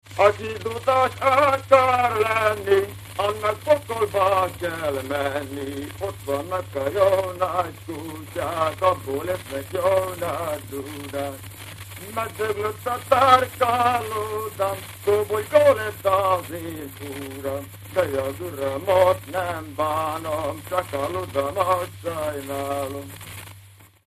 Dudanóta-kanásztánc stílus
A dallam kis motívumokból, mozaikszerű szerkesztéssel épül fel.
A. Duda-ritmus